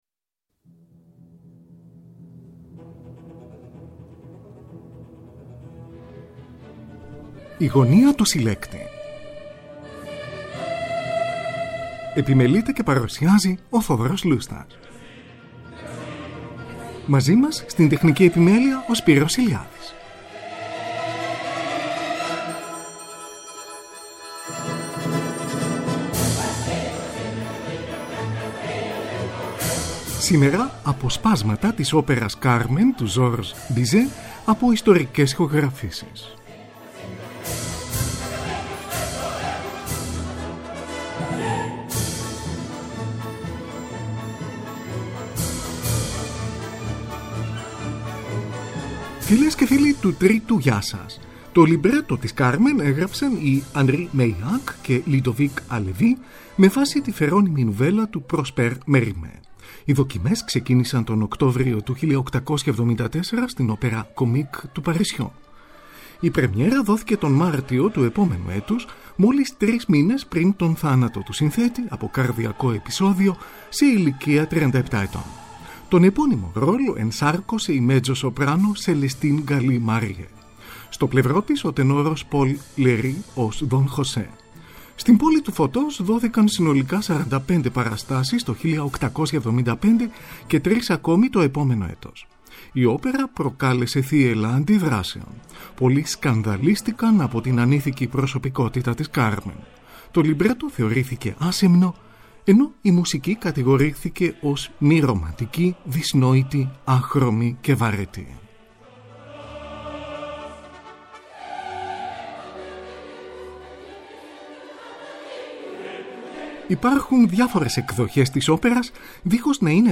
Ντουέτο του Don José και της Micaëla, Parle-moi de ma mère!, από την πρώτη πράξη.
“Άρια του λουλουδιού” του Don José, από τη δεύτερη πράξη. Aκούγονται μεταξύ άλλων τρεις επιφανείς Έλληνες τραγουδιστές του παρελθόντος